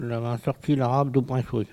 collecte de locutions vernaculaires